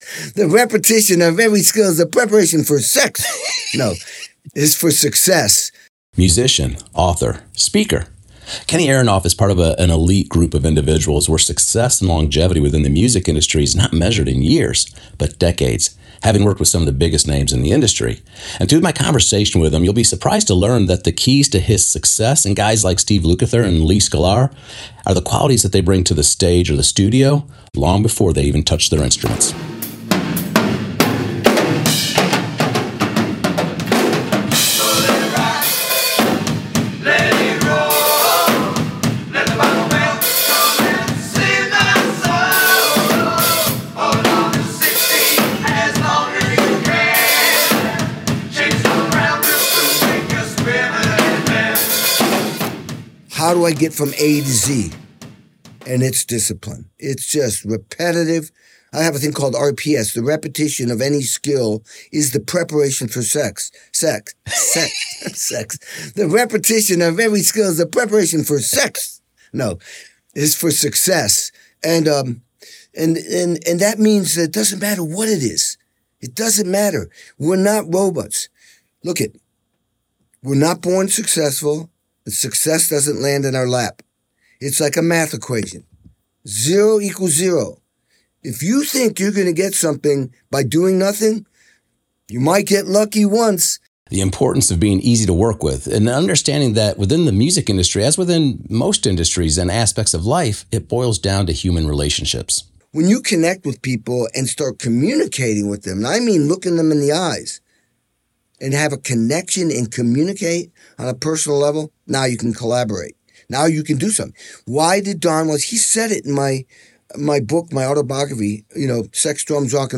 In this entertaining episode of the Soundcheck Podcast, we sit down with the legendary drummer Kenny Aronoff.